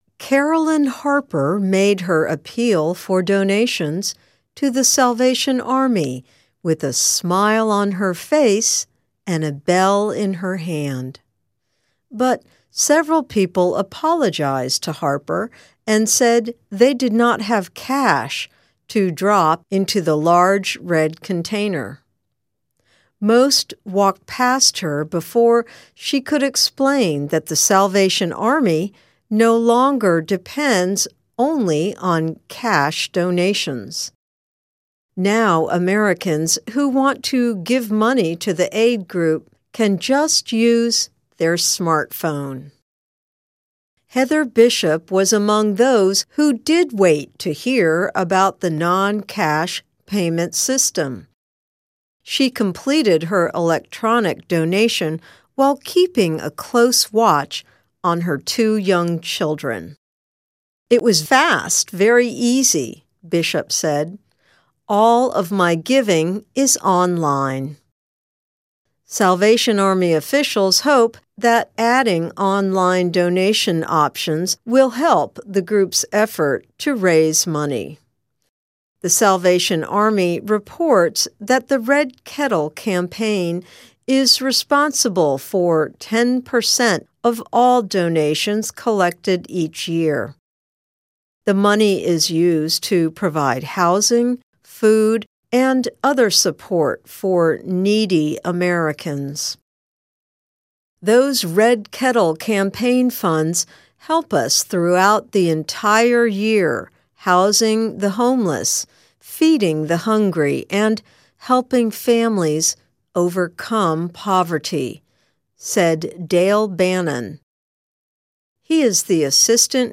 News
慢速英语:救世军现在接受流动捐款